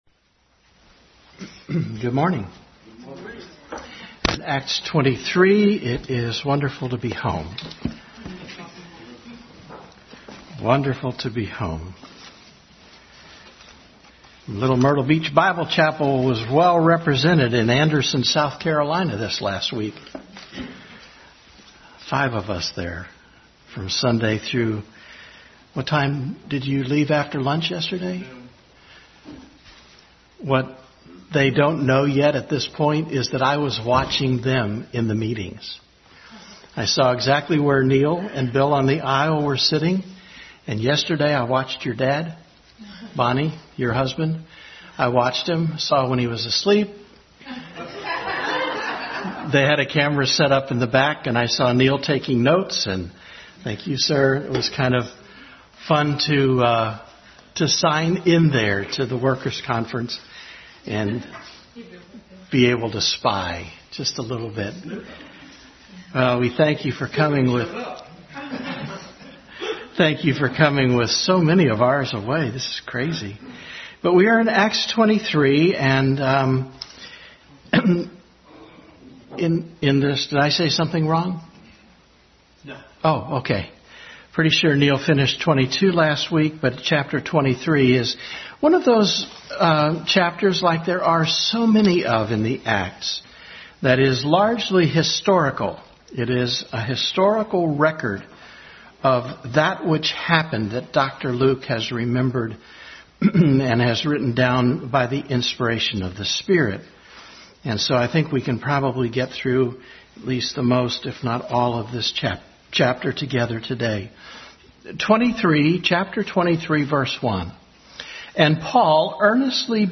Bible Text: Acts 23:1-35 | Regular study in Acts during Sunday Schoo; hour.
Acts 23:1-35 Service Type: Sunday School Bible Text